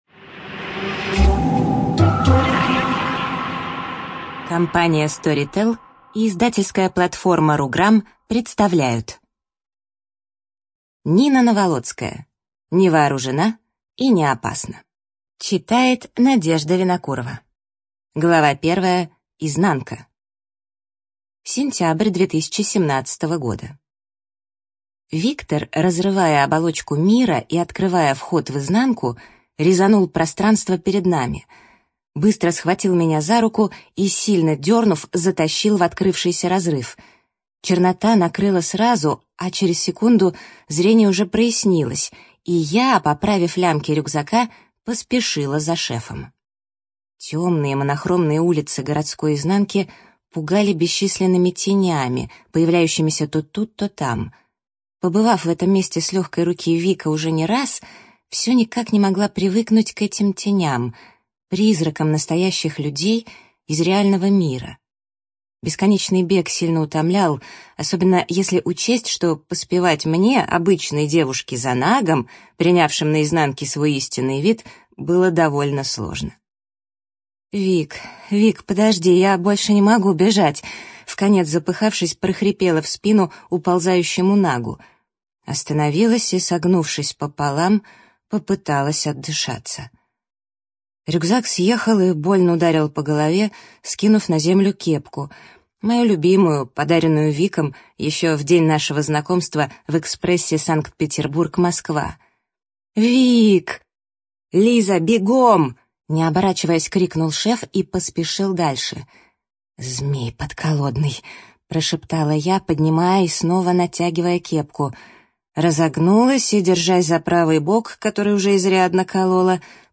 Студия звукозаписиStorytel